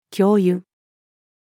教諭-female.mp3